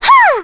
Ophelia-Vox_Attack4.wav